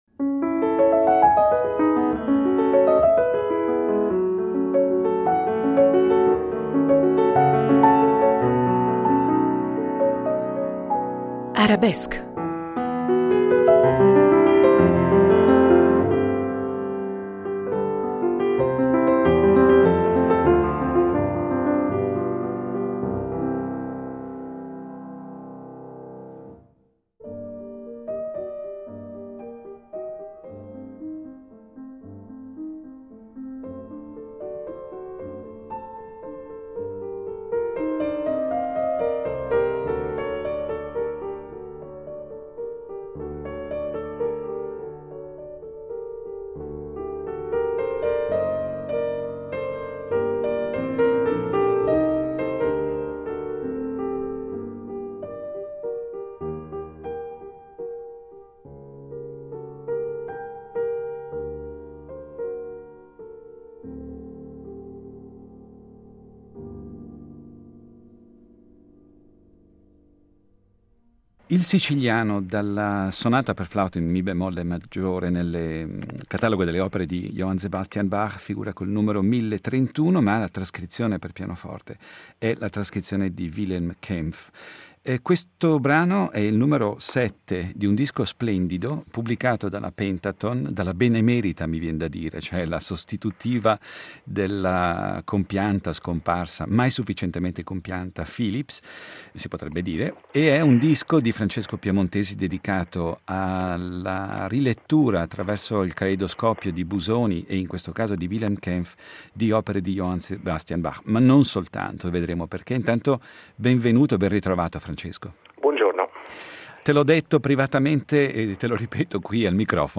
Incontro con Francesco Piemontesi